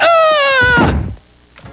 AHHH!_FI.WAV